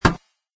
metal2.mp3